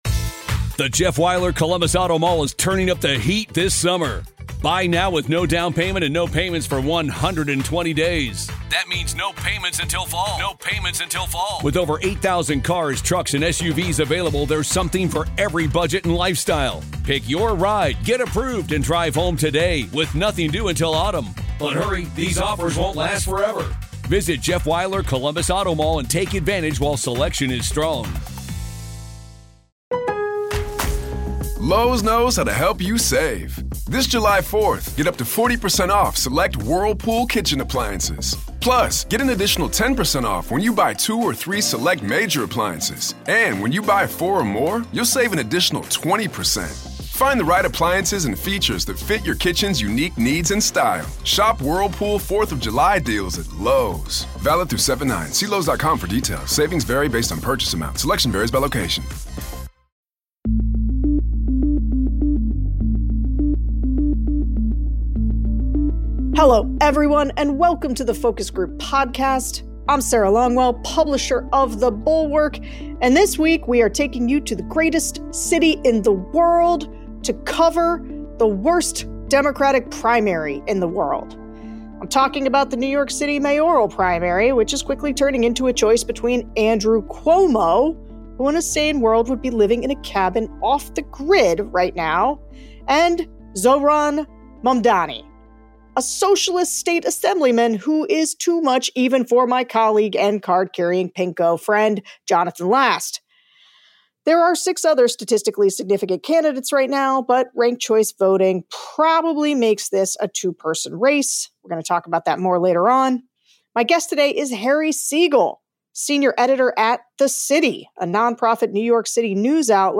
a wide-ranging conversation about New York City's politics today.